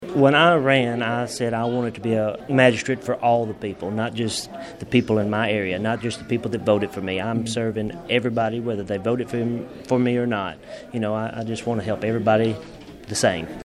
click to download audioDistrict Four Magistrate J.E. Pryor, who is running for a second term, says meeting residents face-to-face is an important part of serving the community.